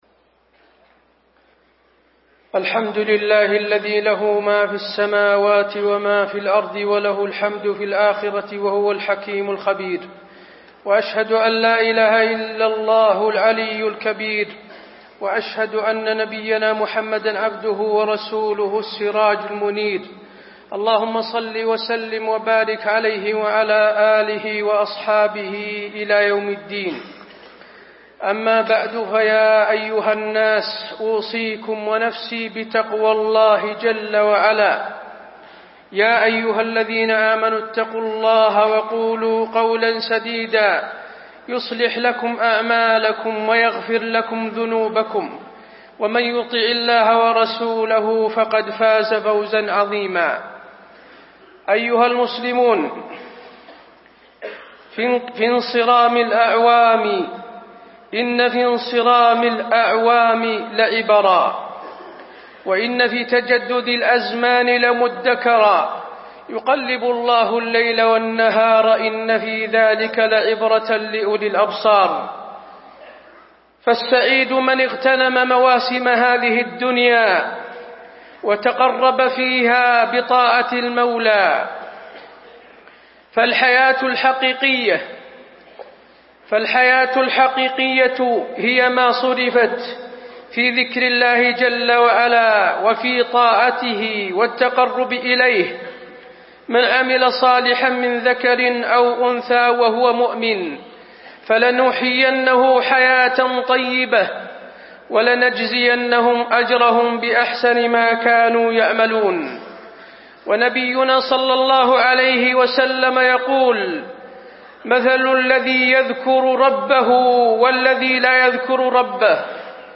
تاريخ النشر ٧ محرم ١٤٣٣ هـ المكان: المسجد النبوي الشيخ: فضيلة الشيخ د. حسين بن عبدالعزيز آل الشيخ فضيلة الشيخ د. حسين بن عبدالعزيز آل الشيخ اغتنام العمر بالطاعات